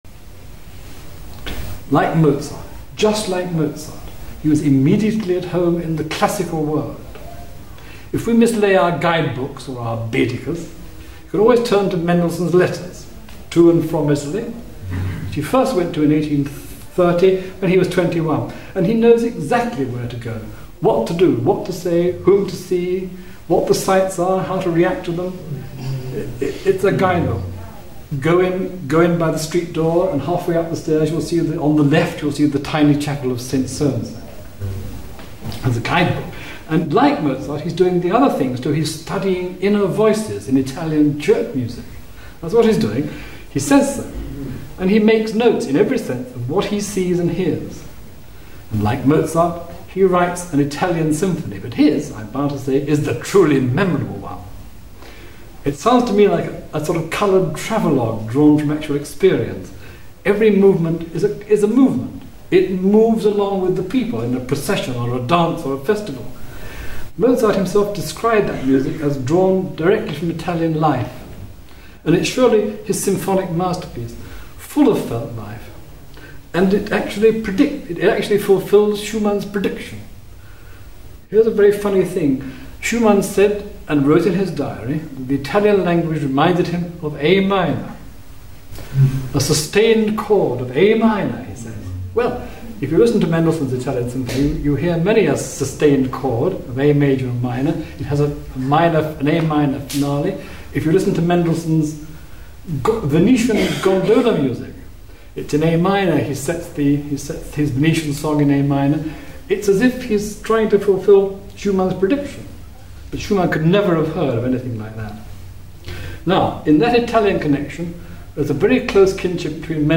Lecture held at the William Walton Foundation, La Mortella, 6 September 1991